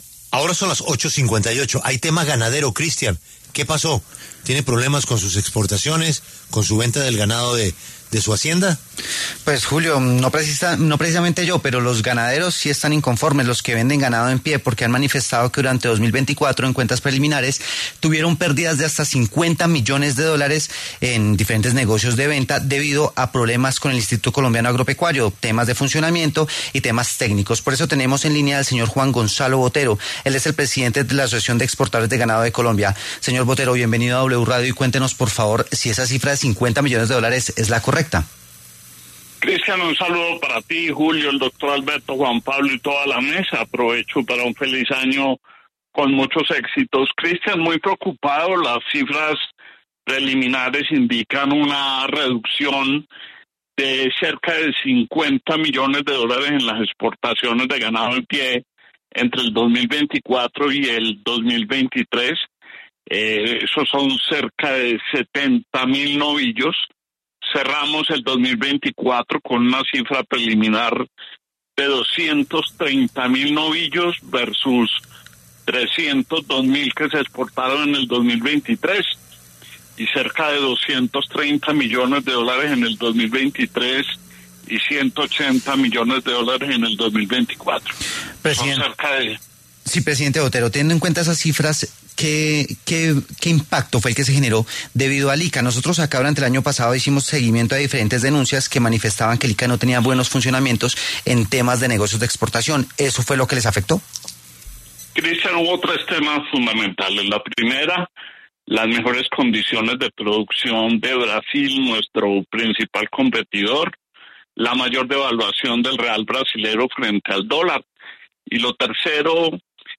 En entrevista con La W